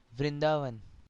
Vrindavan (pronounced [ʋɾɪnˈdɑːʋən]
Vrindavan.ogg.mp3